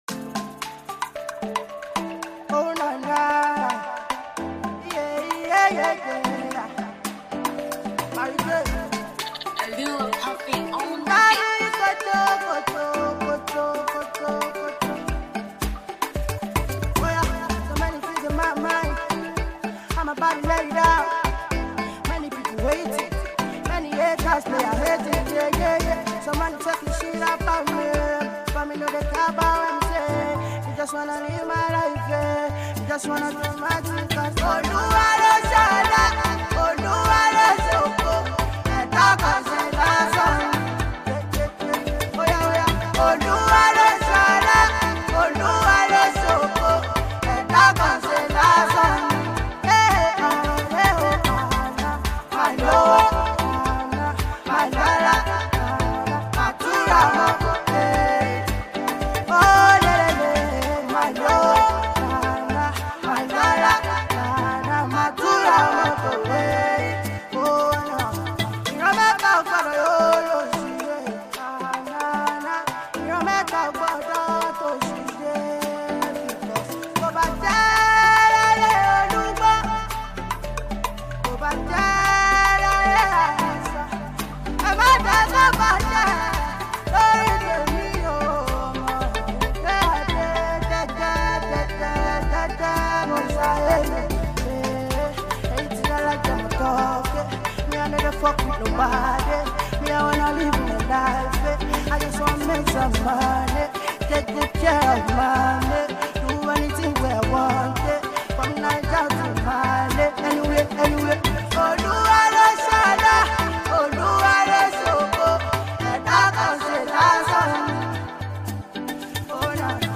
soul-soothing song